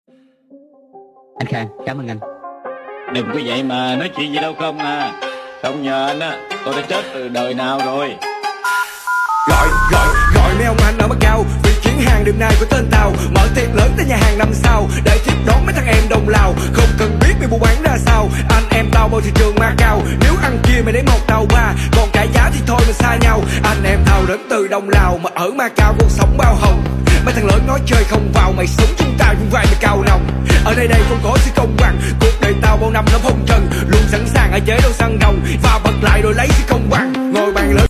Nhạc Chuông Remix